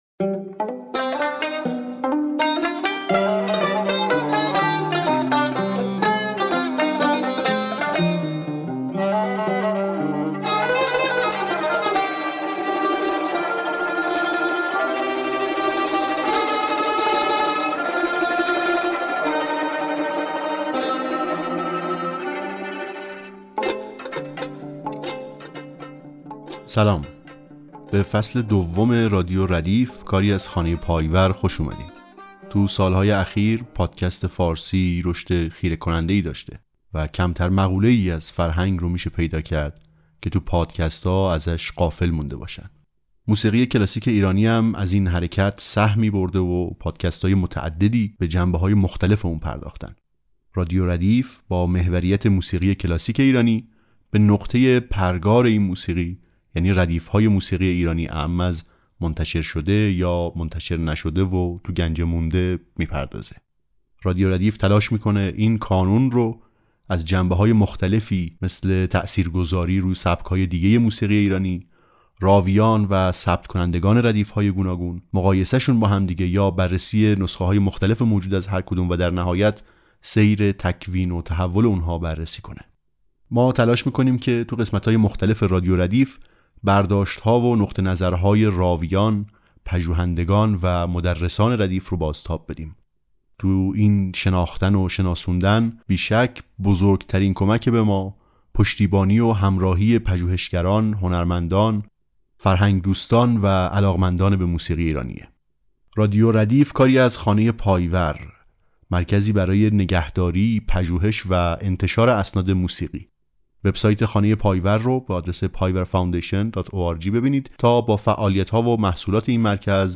ادامه موضوع ضبط‌های سال ۱۲۸۴ شمسی همراه بررسی دیگر نمونه‌های صوتی به‌جامانده از هنرمندان در این سال، که عملاً مکمل شماره قبلی است، موضوع قسمت چهارم فصل دوم پادکست رادیو ردیف است. با توجه به تنوع عناوین، هنرمندان و گونه‌های ضبط‌شده در این آثار، ضمن مرور محتوای متنوع آنها، برخی نمونه‌های صوتی به‌یادگارمانده نیز در این شماره قابل شنیدن است.